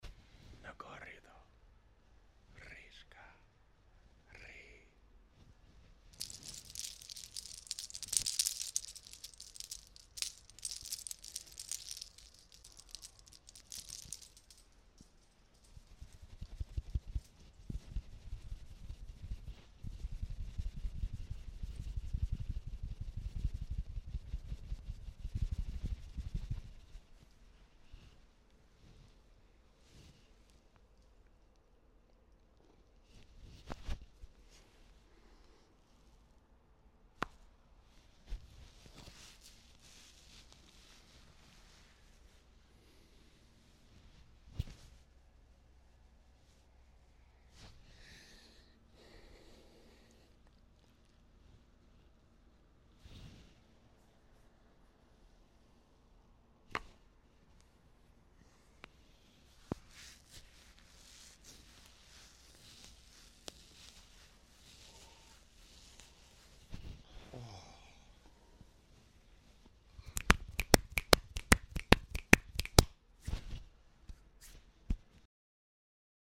Part 1: Sumo Wrestler ASMR Sound Effects Free Download